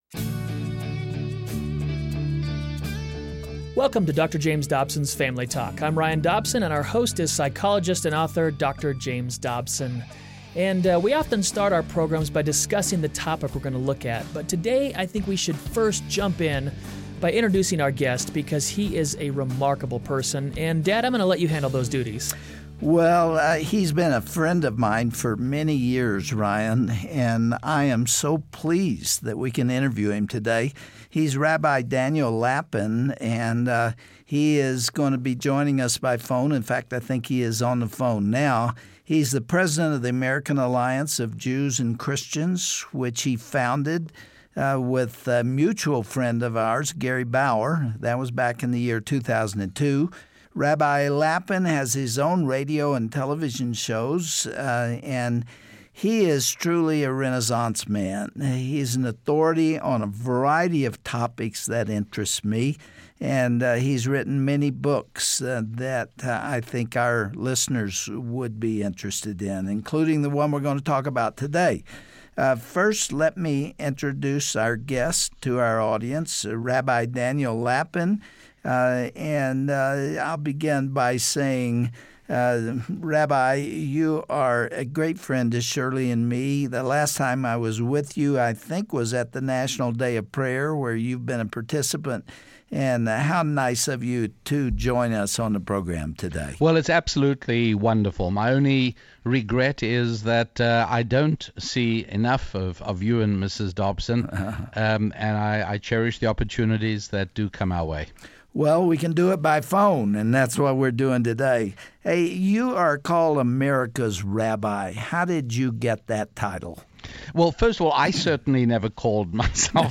Sometimes English translations of the Bible miss amazing truths hidden in the original Hebrew and Greek. On the next edition of Family Talk, Dr. James Dobson will interview Rabbi Daniel Lapin about Buried Treasure in the Biblical text.